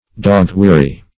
Dog-weary \Dog"-wea`ry\, a. Extremely weary.